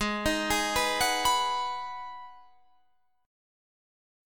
Abm7 chord